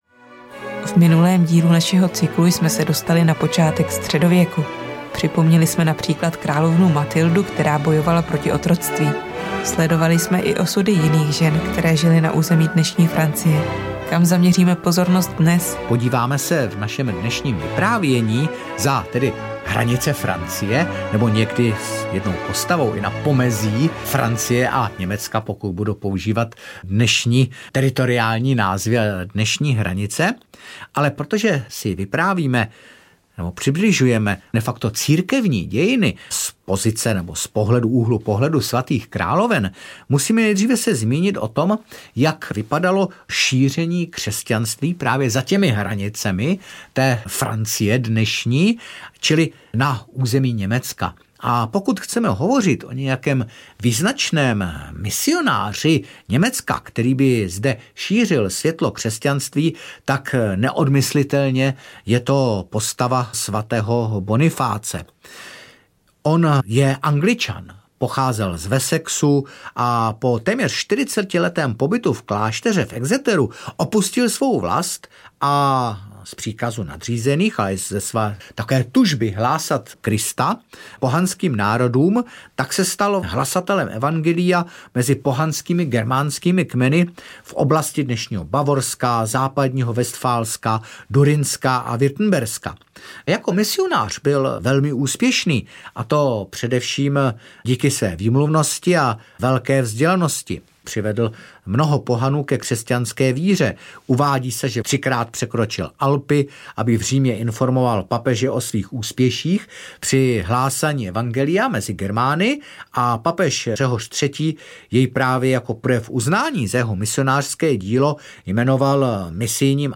Svaté královny audiokniha
Ukázka z knihy